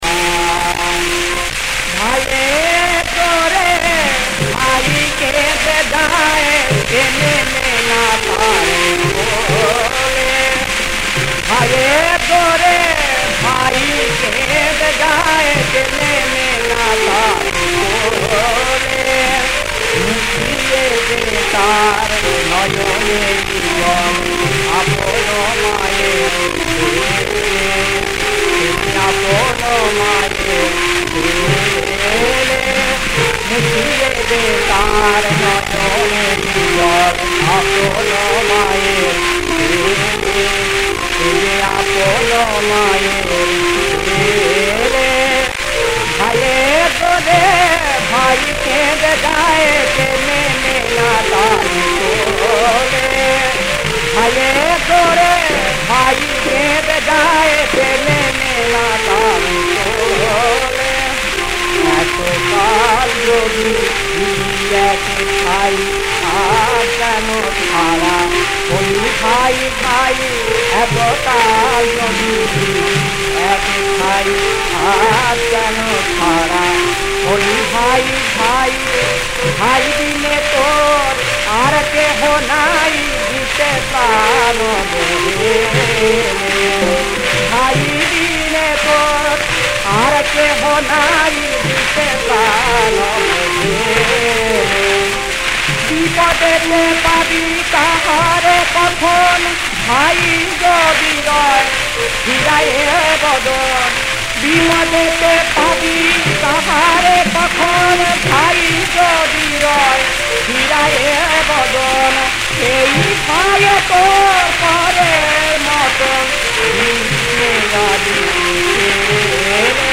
• বিষয়াঙ্গ: ভক্তি (ইসলামী গান)
• তাল: কাহারবা
• গ্রহস্বর: র্সা